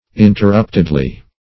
Search Result for " interruptedly" : The Collaborative International Dictionary of English v.0.48: Interruptedly \In`ter*rupt"ed*ly\, adv.
interruptedly.mp3